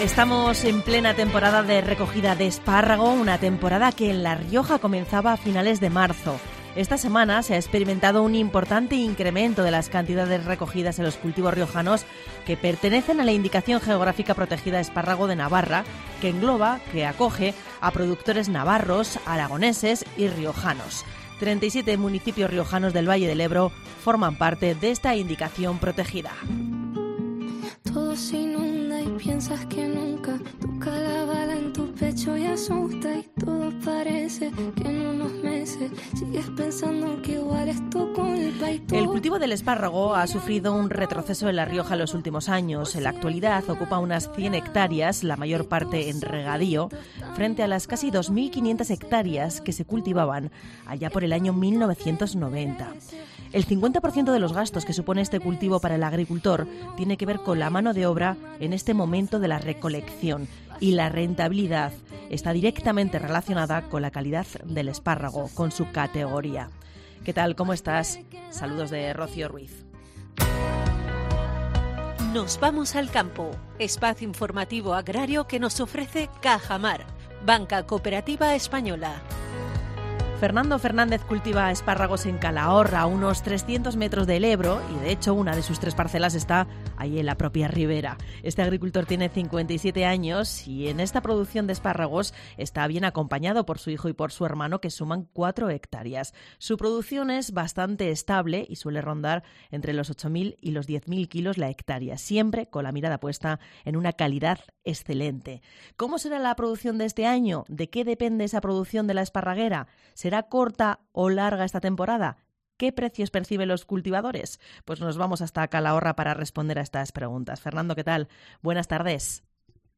Un cultivador de espárrago de La Rioja: "Con una buena esparraguera de buen calibre sí es rentable"